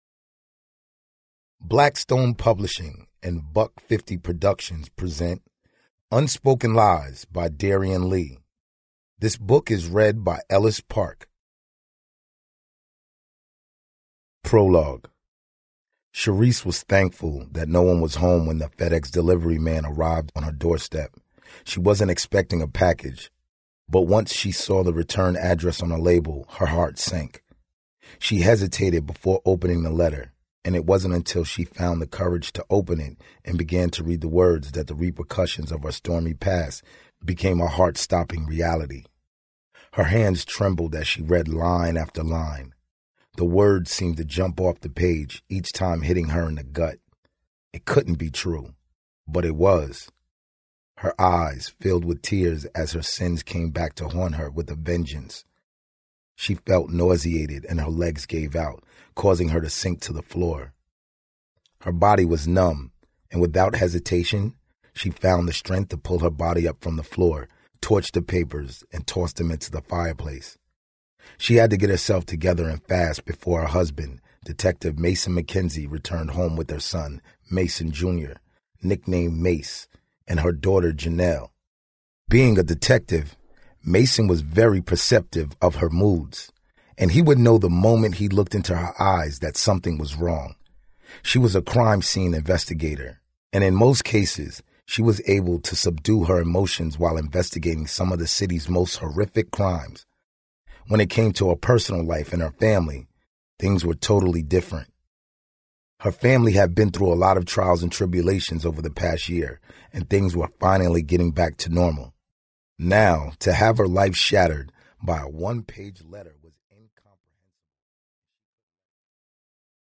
Spoken word.
Audiobooks.